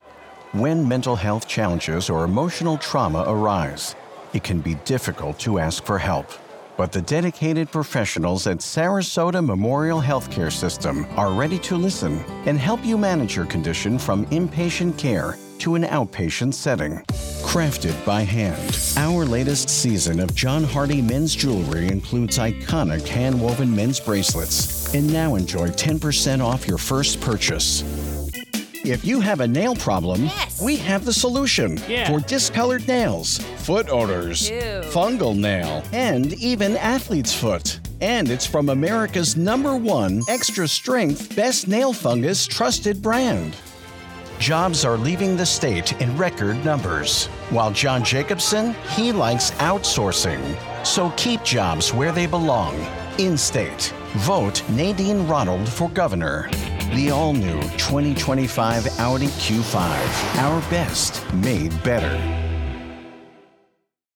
Anglais (américain)
Croyable
Sincère
Énergique